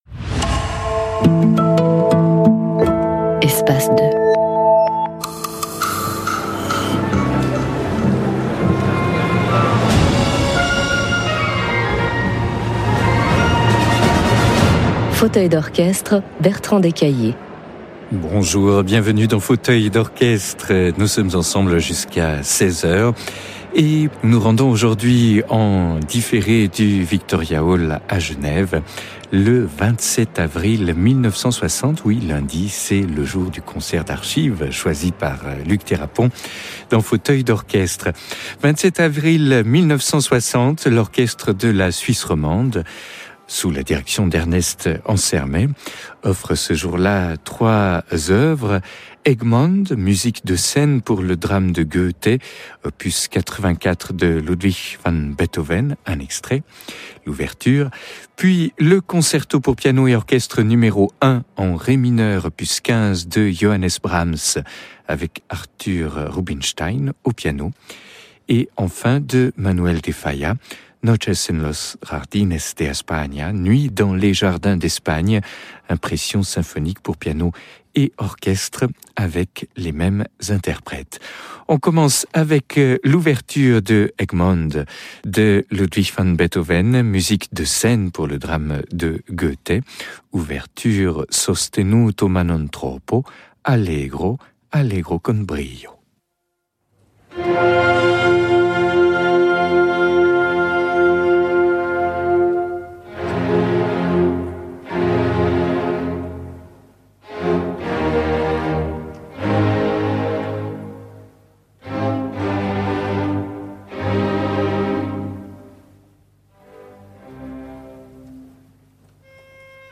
Over to Geneva Switzerland for a historic concert by Orchestre de la Suisse Romande, conducted by their Music Director of many years Ernest Ansermet and featuring the legendary Artur Rubinstein, piano in a concert recorded April 27, 1960. It was rebroadcast as part of the ongoing RTS Espace 2 radio series Fauteuil d’Orchestre, which runs historic concerts every week (hint-hint: check them out).